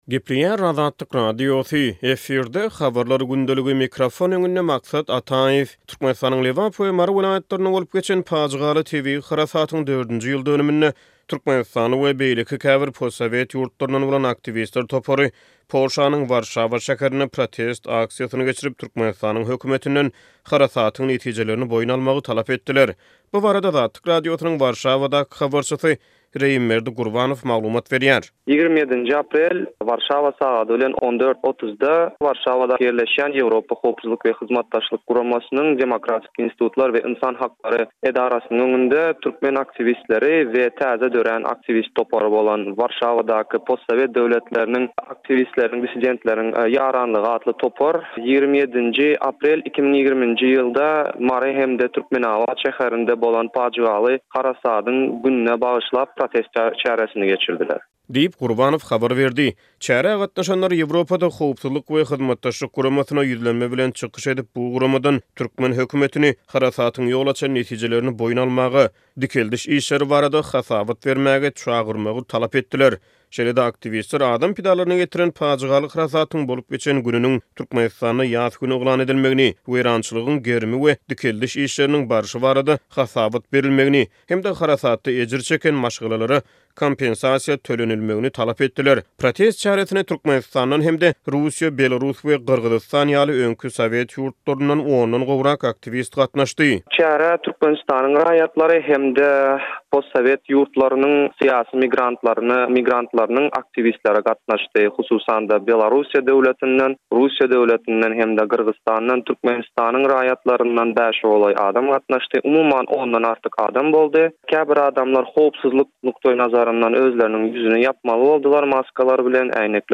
Diňle: Aktiwistler harasadyň dördünji ýyl dönüminde protest çäresini geçirip, ýüzlenme bilen çykyş etdiler